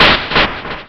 贡献 ） 分类:游戏音效 您不可以覆盖此文件。